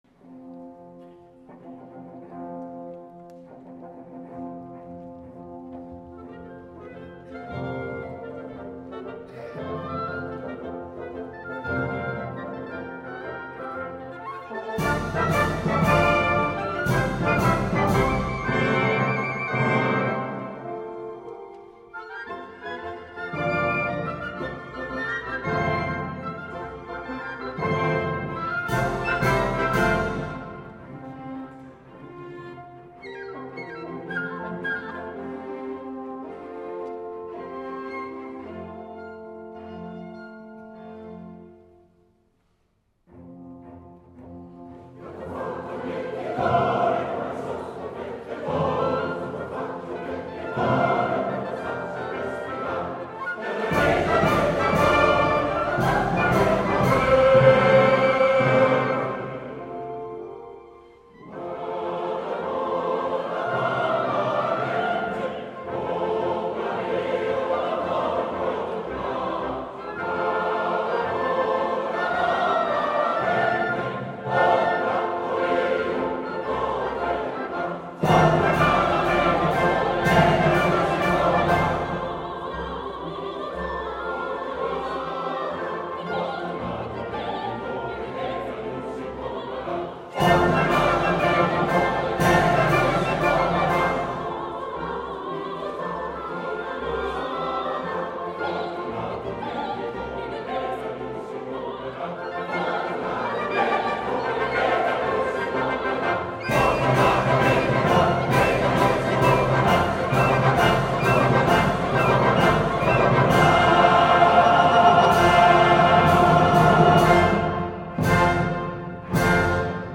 Het Rotterdams Operakoor bestaat 95 jaar en opent dit jubileumjaar met zijn 50ste Nieuwjaarsconcert in de Doelen te Rotterdam.
Zij zingen zorgvuldig en nauwlettend, genietend vol enthousiasme en spontaniteit.
De rol van het orkest in ‘L’Elisir d’Amore’ werd door Donizetti gereduceerd tot een eenvoudige begeleiding en het orkest Accompagnato doet zijn naam wat dat betreft eer aan.